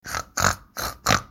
Oink 40664 (audio/mpeg)